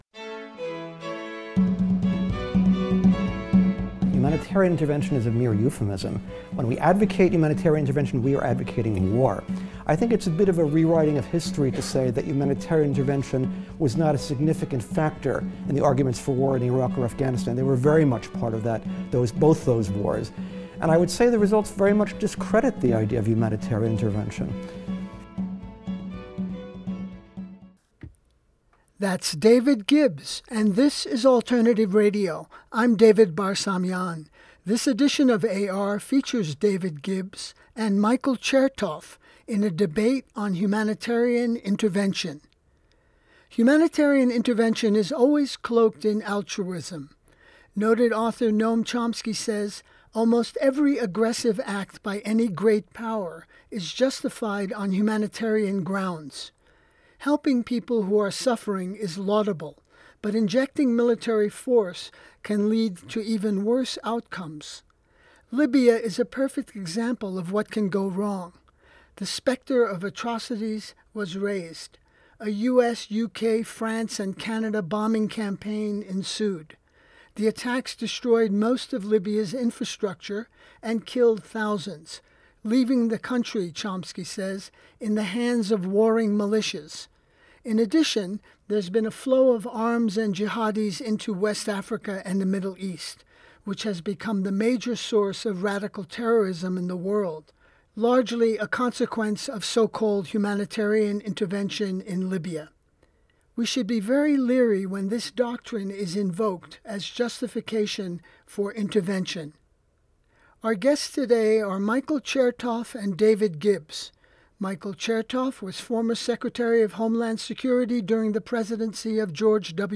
Debate on Humanitarian Intervention